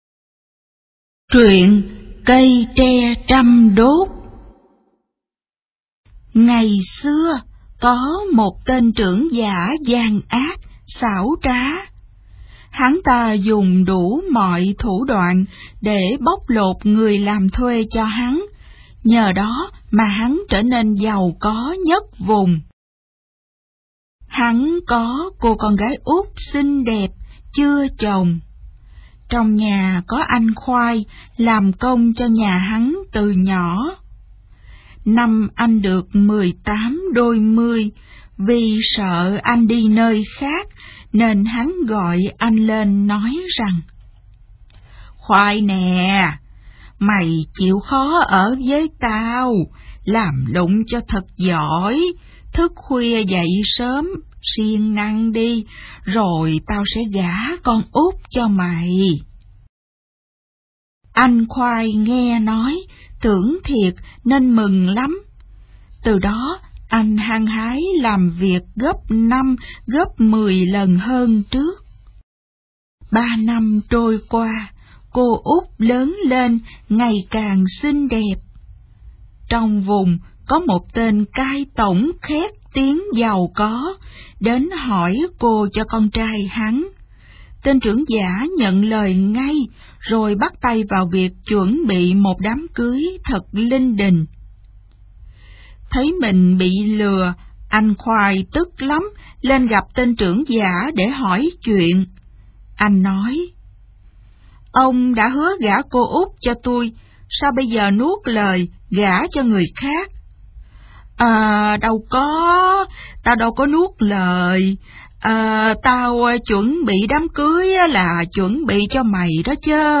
Sách nói | Dế mèm phưu lưu ký